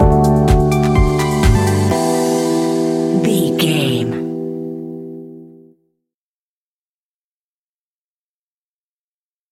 Aeolian/Minor
uplifting
energetic
bouncy
funky
bass guitar
synthesiser
electric piano
drum machine
funky house
groovy
upbeat